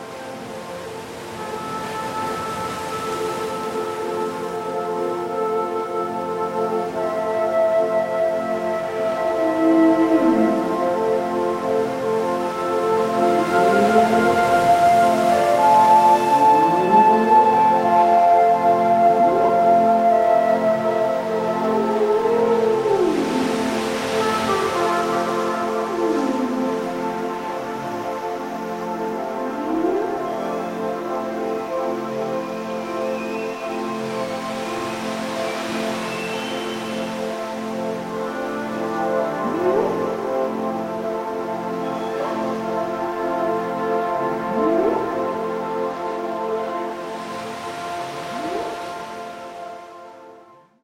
blissful music